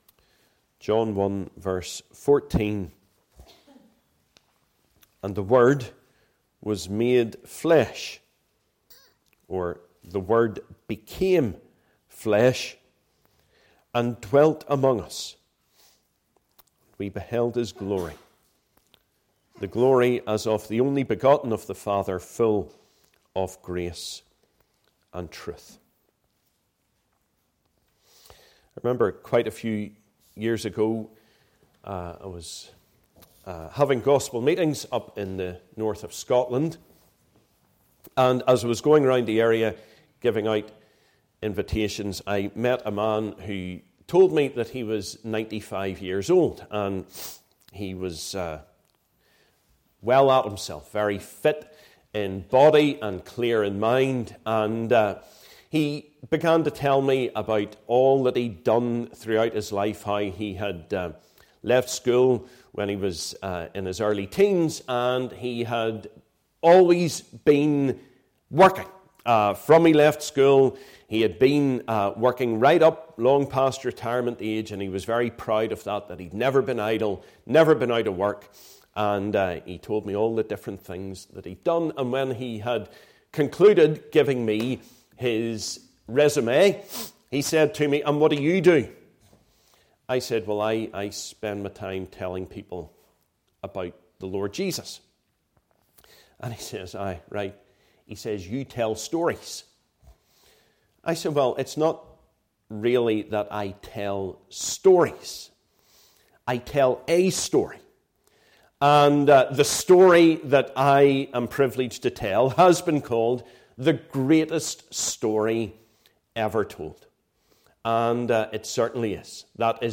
Passage: John 1:1-21 Meeting Type: Ministry